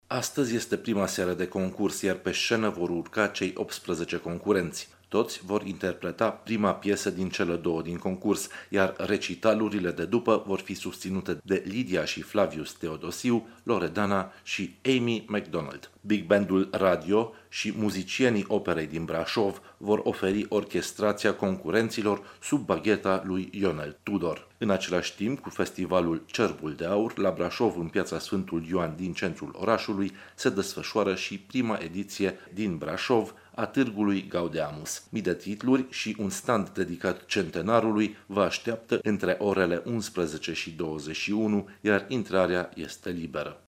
Corespondentul RRA